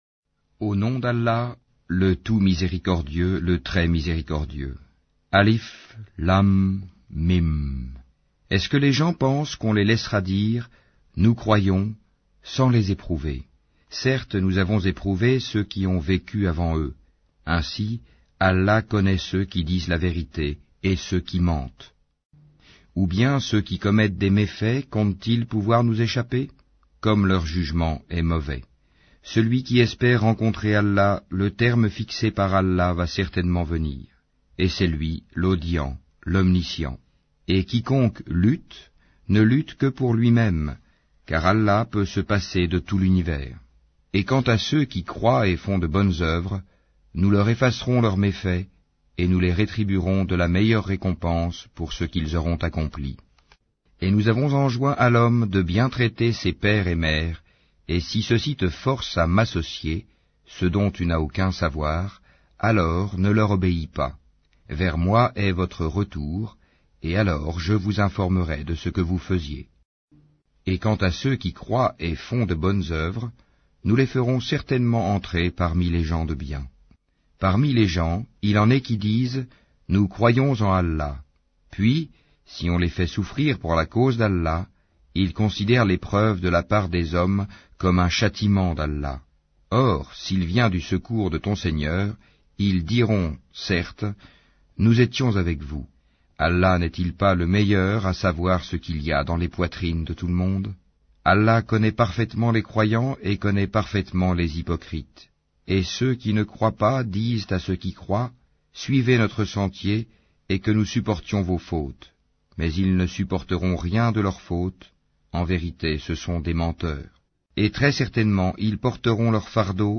Al-Ankabut Lecture audio